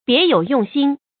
注音：ㄅㄧㄝ ˊ ㄧㄡˇ ㄩㄥˋ ㄒㄧㄣ